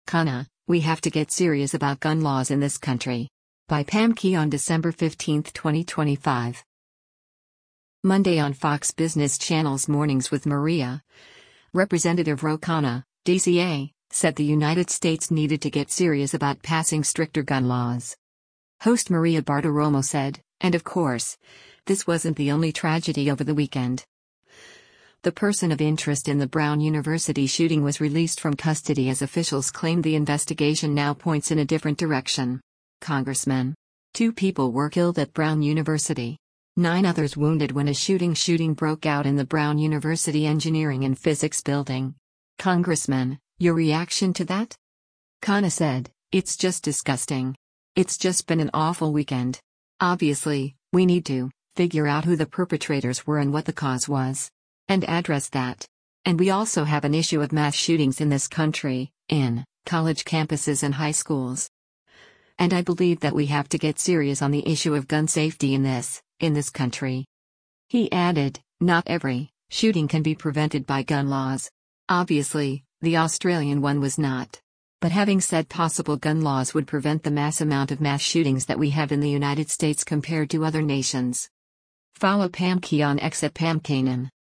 Monday on Fox Business Network’s “Mornings With Maria,” Rep. Ro Khanna (D-CA) said the United States needed to “get serious” about passing stricter gun laws.